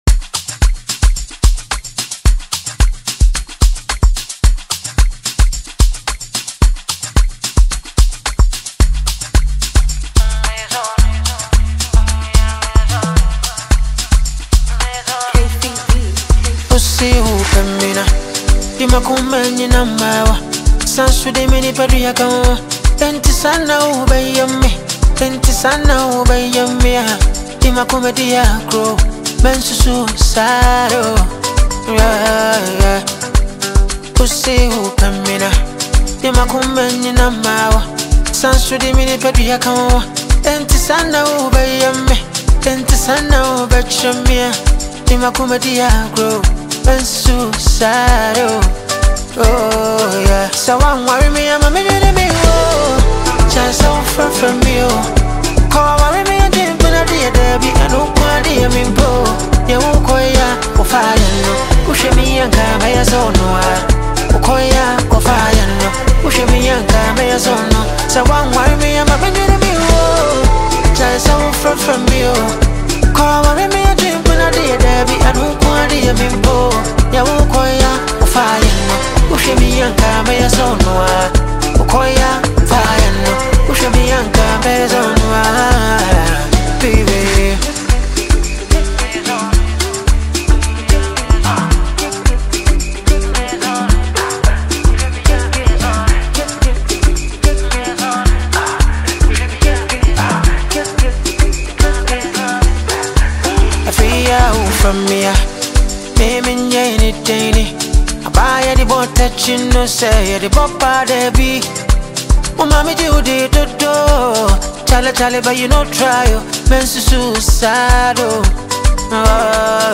The song is a smooth, emotional, and melodious tune
blends highlife, Afro-soul, and R&B influences